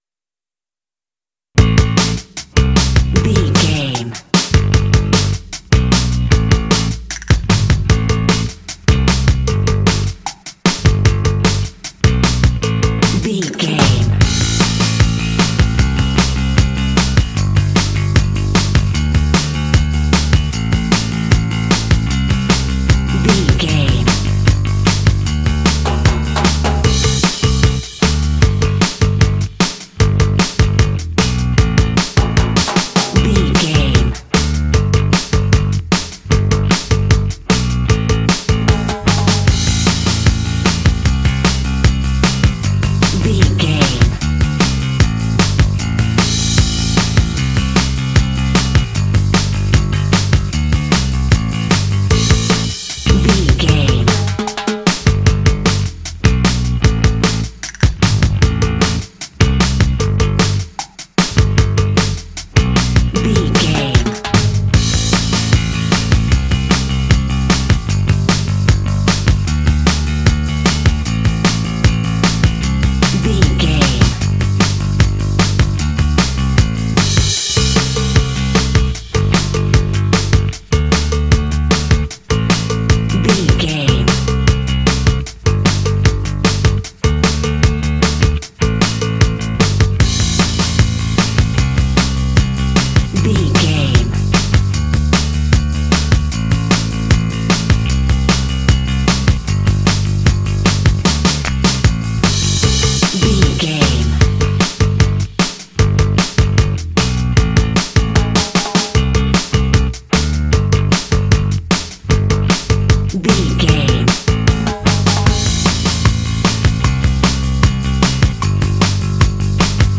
Aeolian/Minor
bouncy
groovy
drums
electric guitar
bass guitar
alternative